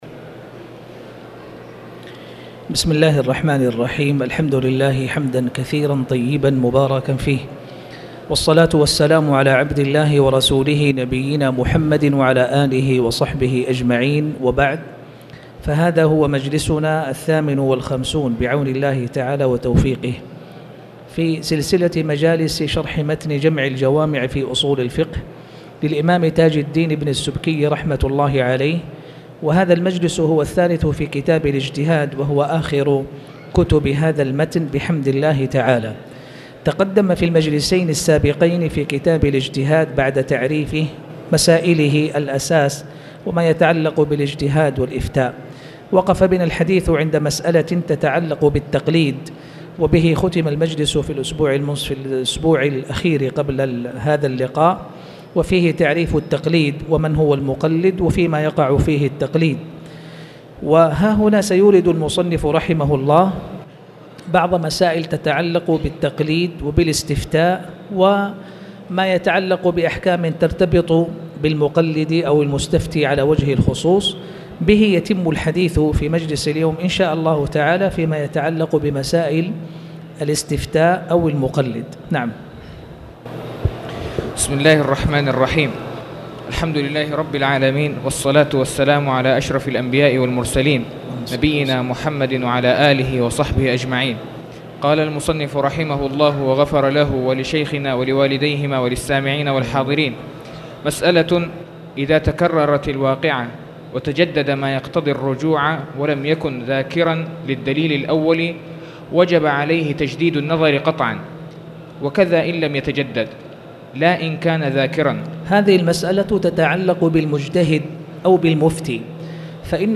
تاريخ النشر ٢٣ جمادى الآخرة ١٤٣٨ هـ المكان: المسجد الحرام الشيخ